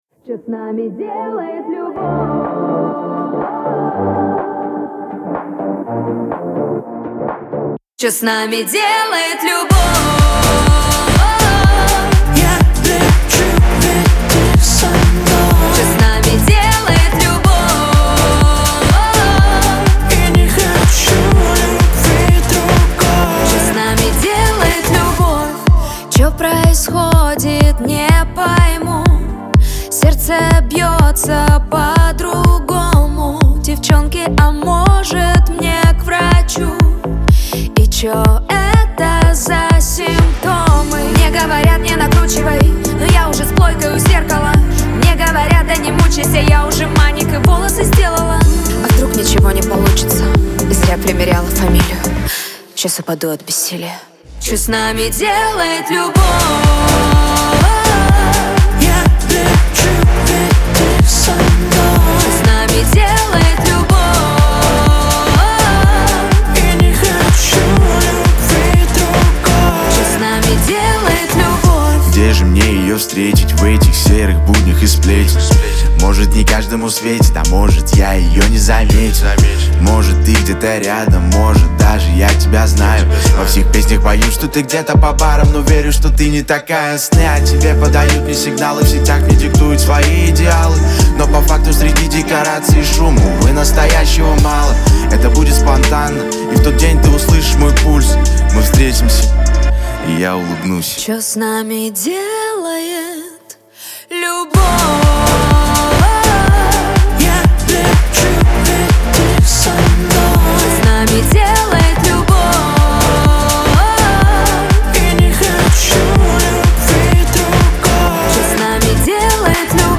эстрада
дуэт , pop